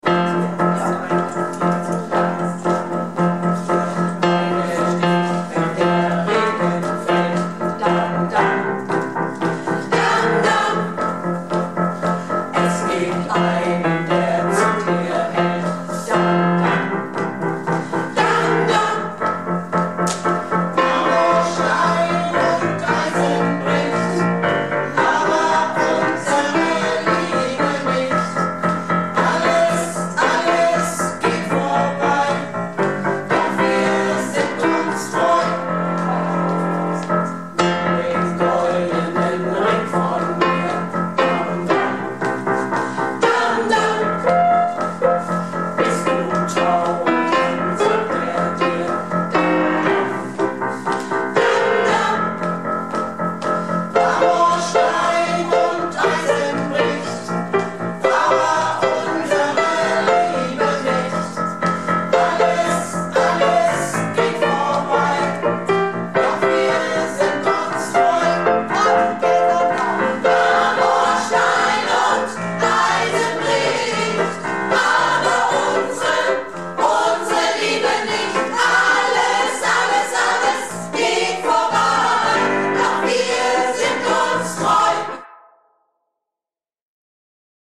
Projektchor "Keine Wahl ist keine Wahl" - Probe am 26.03.19